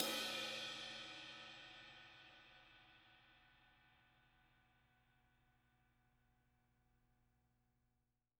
R_B Crash A 02 - Close.wav